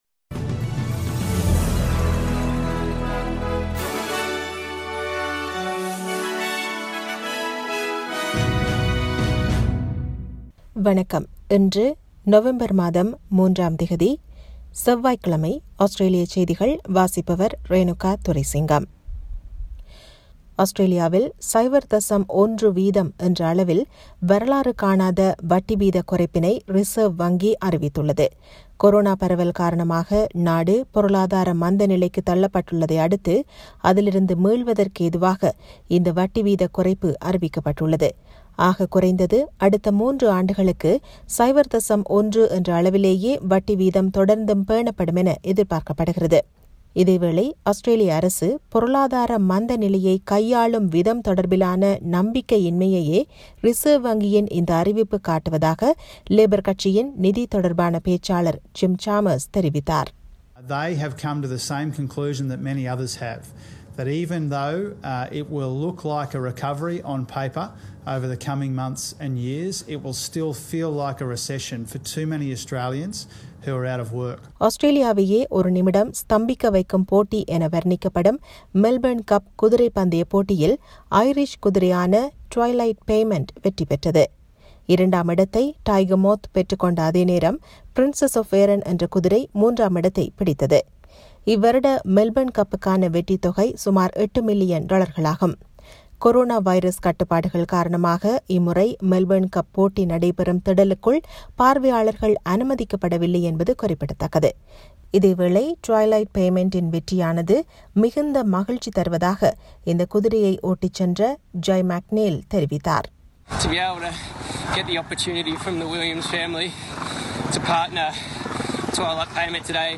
Australian news bulletin for Tuesday 03 November 2020.